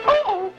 Worms speechbanks
Grenade.wav